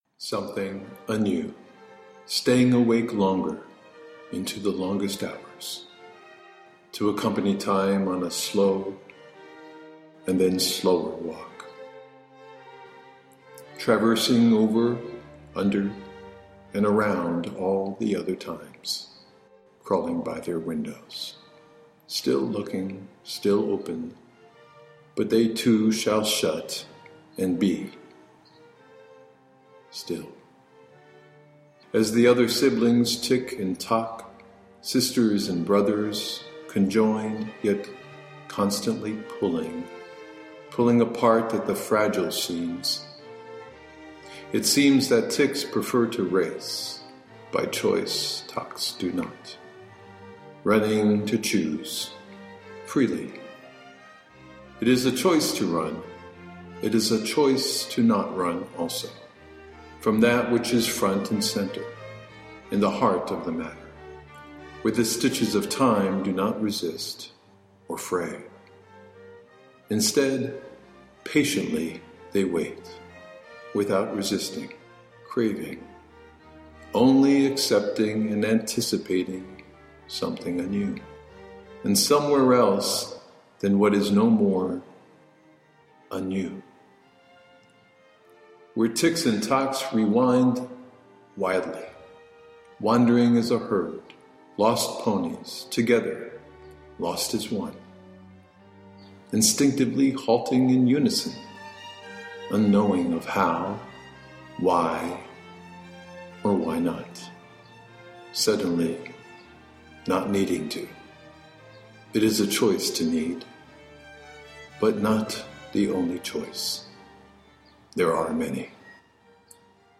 - Baryton Ténor